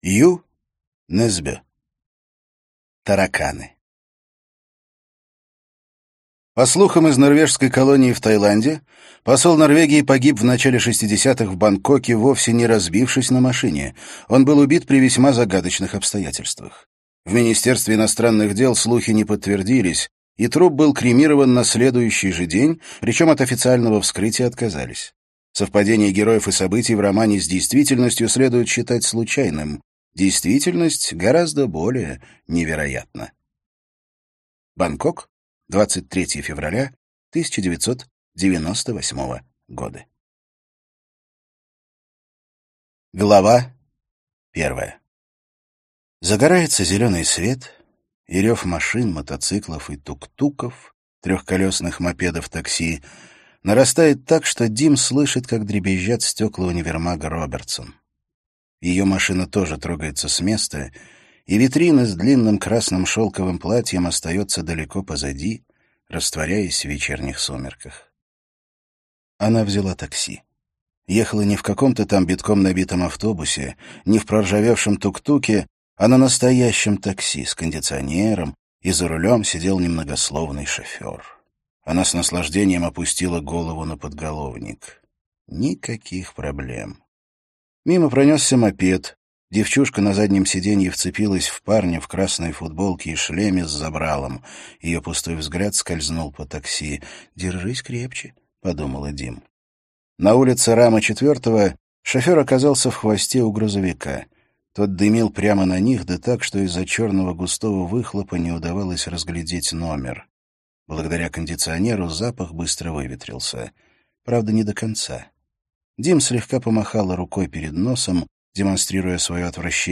Аудиокнига Тараканы - купить, скачать и слушать онлайн | КнигоПоиск